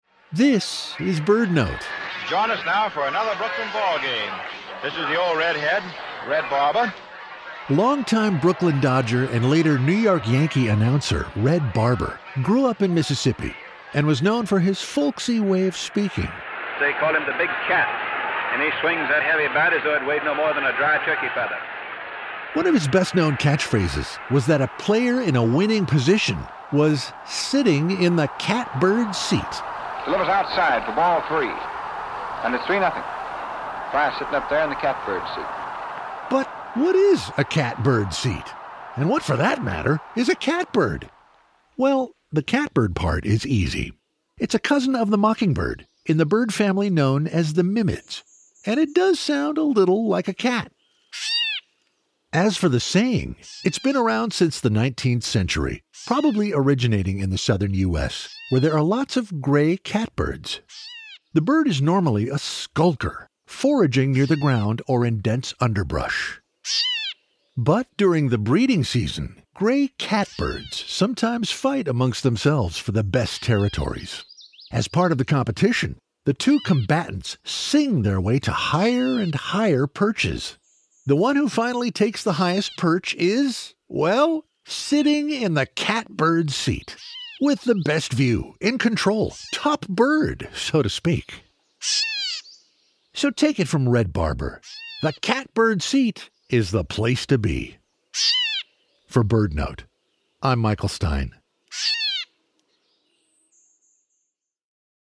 The Gray Catbird is a cousin of the mockingbird, and it does sound a little like a cat. During the breeding season, when it’s protecting its territory, the catbird competes with others of its species. The two combatants sing their way to higher and higher perches.